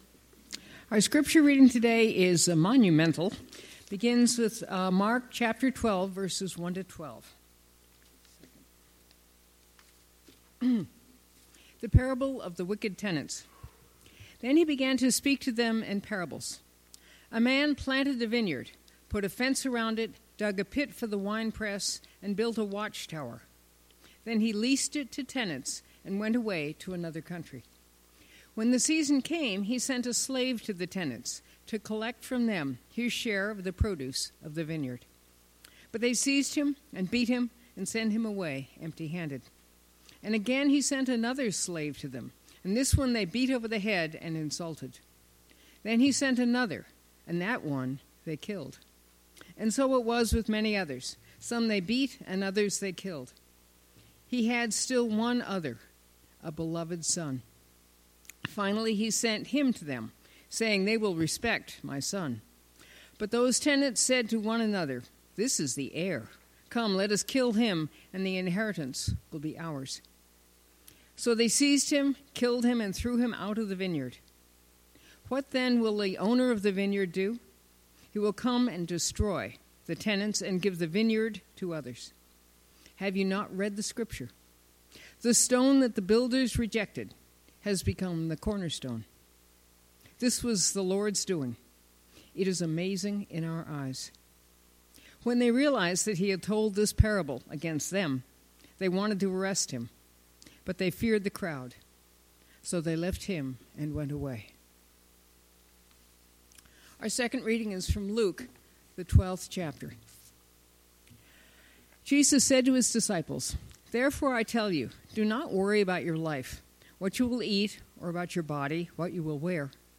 Passage: Mark 12:1-12; Luke 12:22-48 Service Type: Sunday Morning